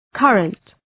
Shkrimi fonetik{‘kɜ:rənt, ‘kʌrənt}